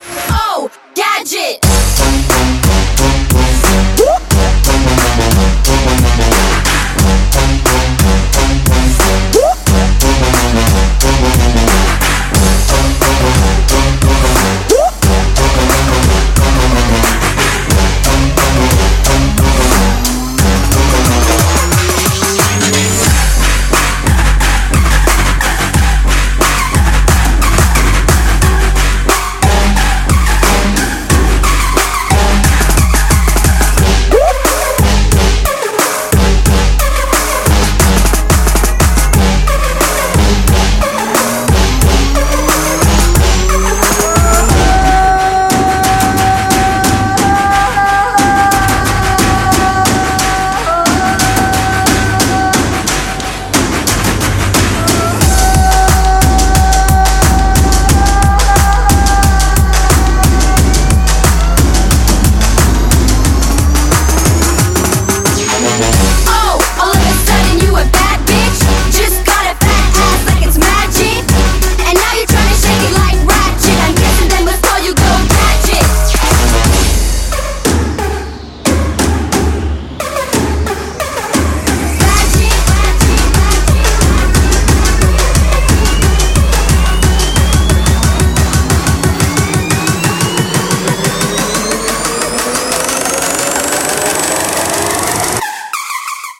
громкие
Trap
качающие
Bass